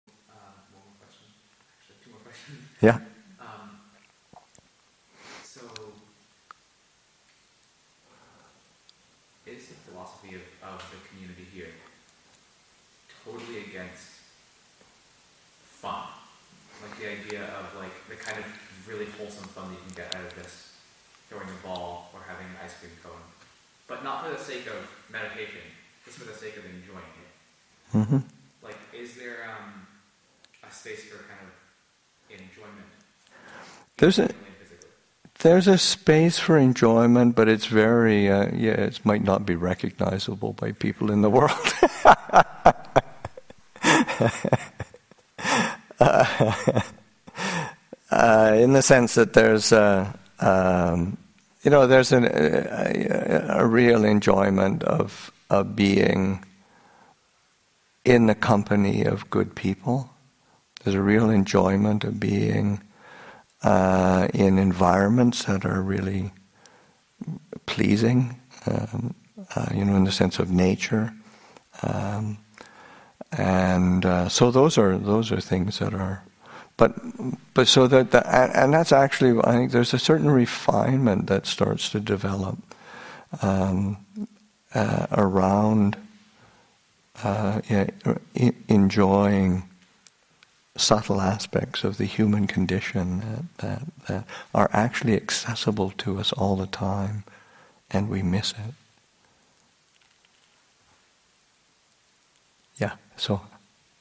Teen Weekend 2017 – Sep. 2, 2017